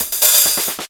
12DR.BREAK.wav